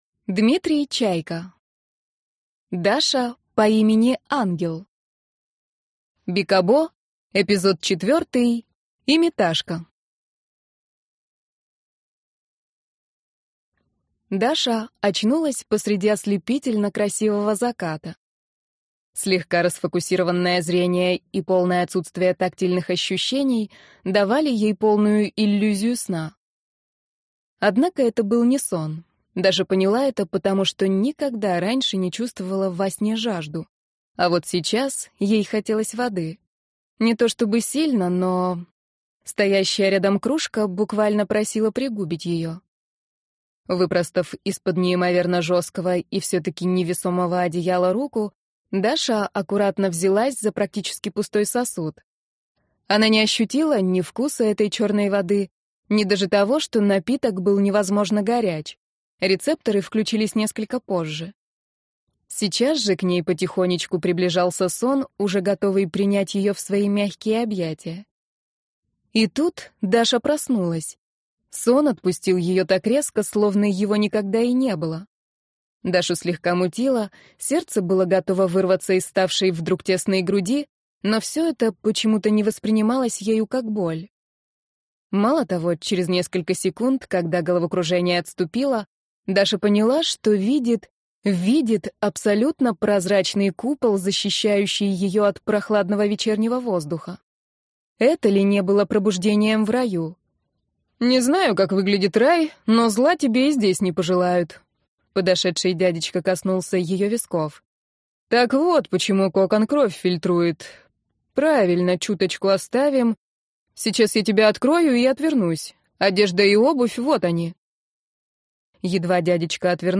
Аудиокнига Даша по имени Ангел | Библиотека аудиокниг